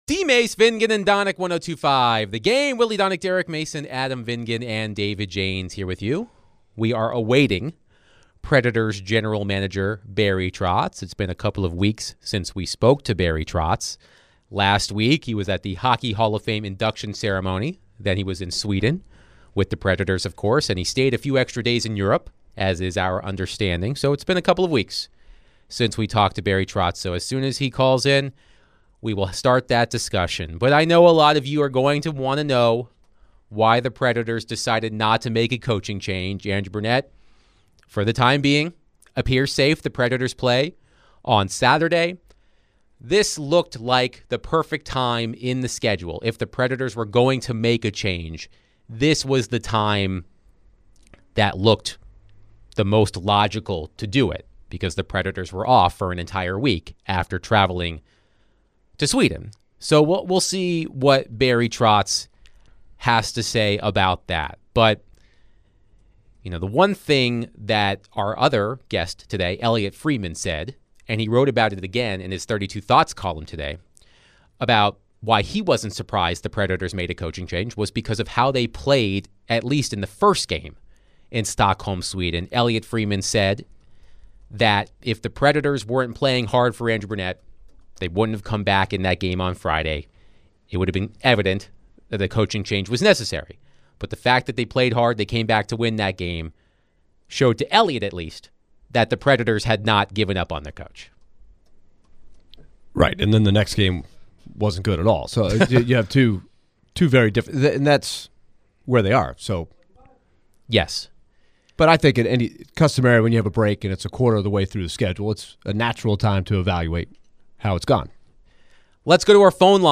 Nashville Predators General Manager Barry Trotz joins DVD to discuss why he has stayed the course with Andrew Brunette, if any players are asking to be waived, and more.